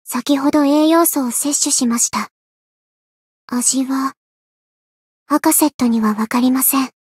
灵魂潮汐-阿卡赛特-问候-晴天下午-亲密.ogg